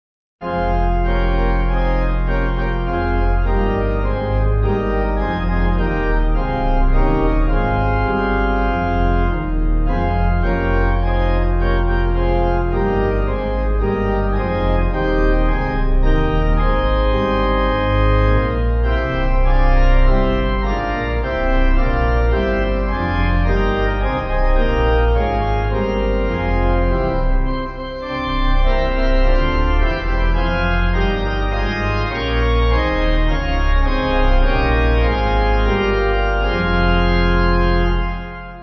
Organ
(CM)   4/Fm